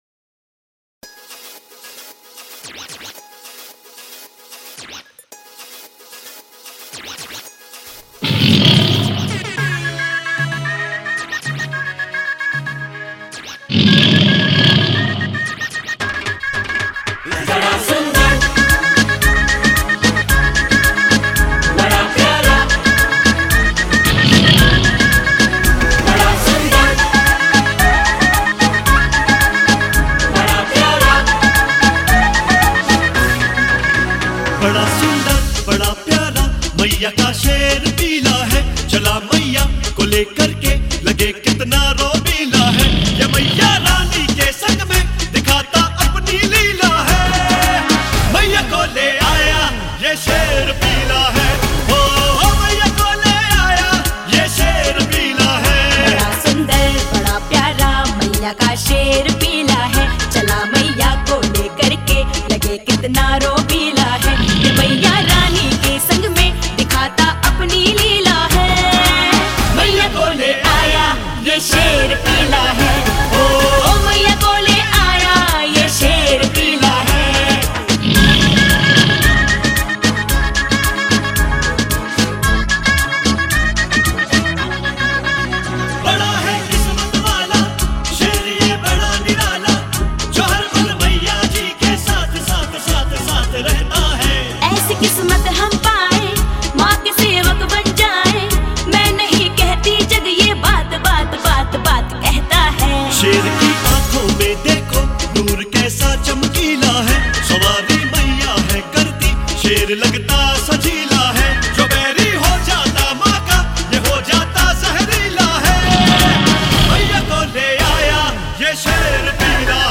bhajan album